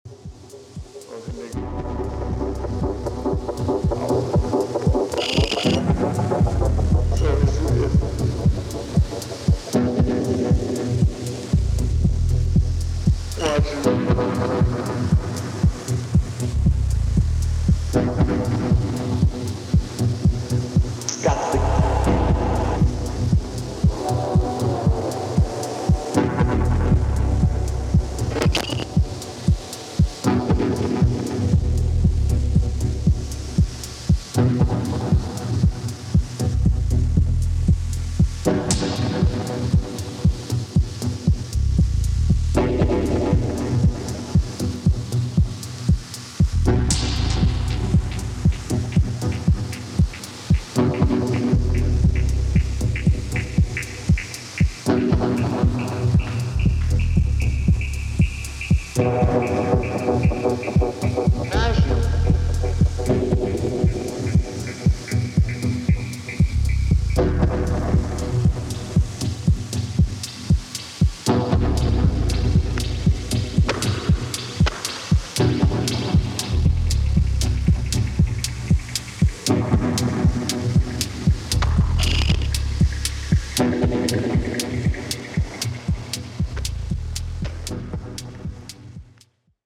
live show in New York City
Electronix Techno Ambient Dub Techno